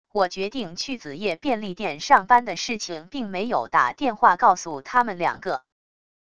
我决定去子页便利店上班的事情并没有打电话告诉他们两个wav音频生成系统WAV Audio Player